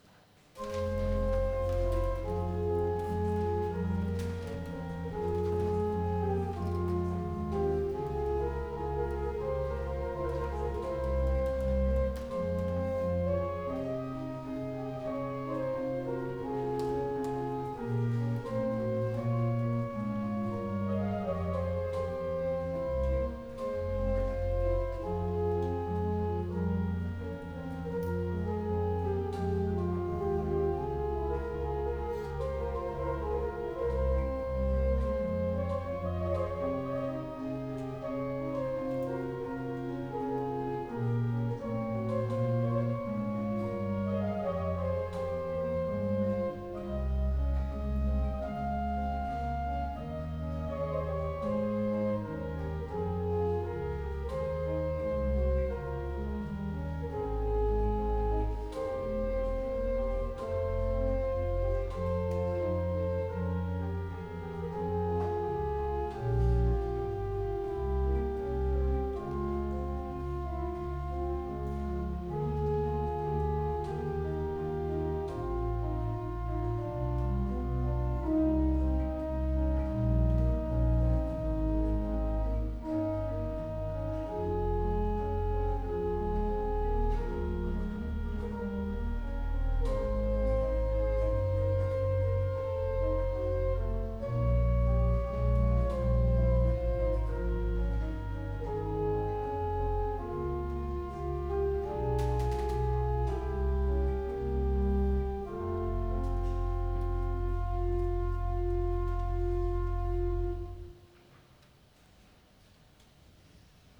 cantate
soprano
Les airs, quant à eux, sont forts.